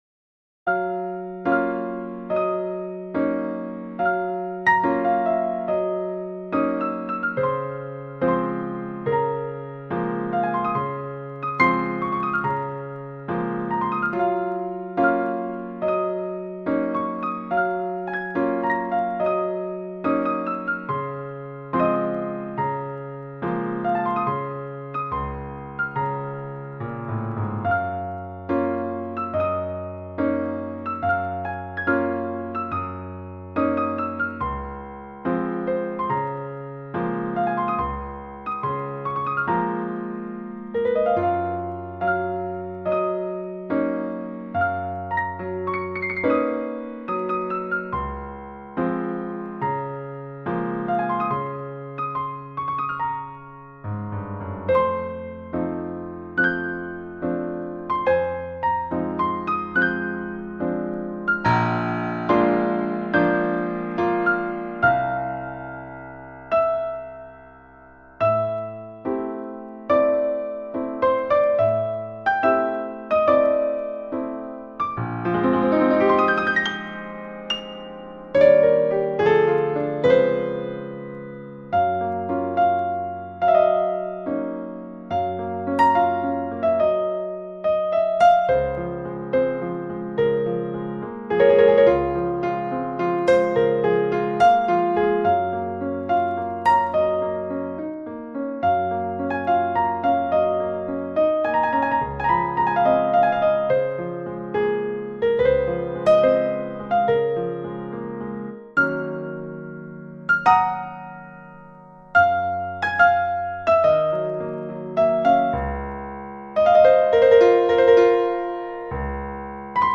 Ноты для фортепиано.
*.mp3 - аудио-файл для прослушивания нот.